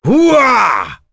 Phillop-Vox_Skill1.wav